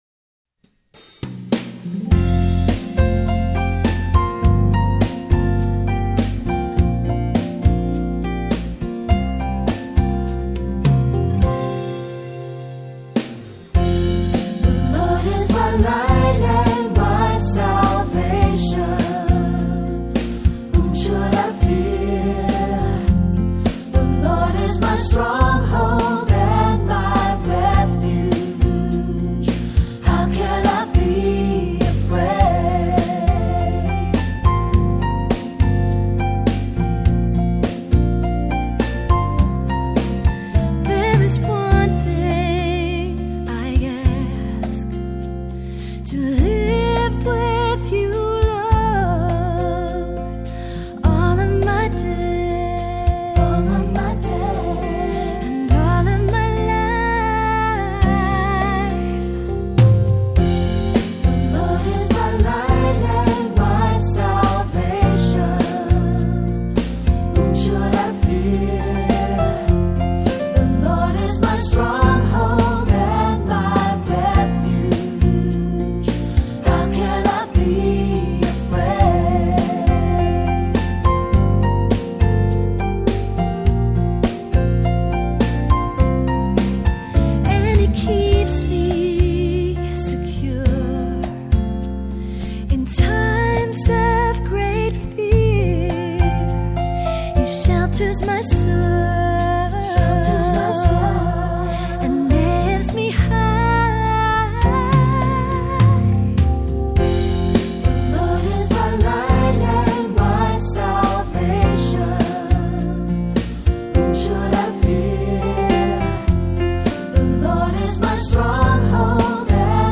Voicing: Unison; Cantor; Assembly